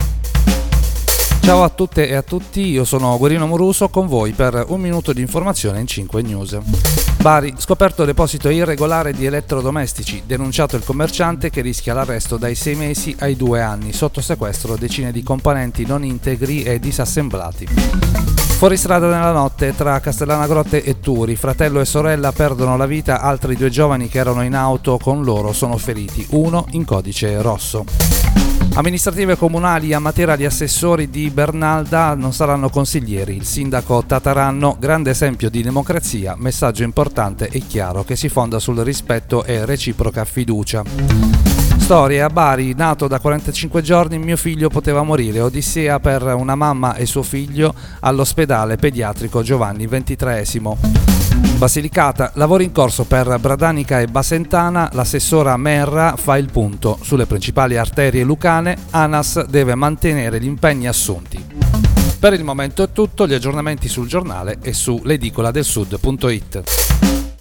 Giornale radio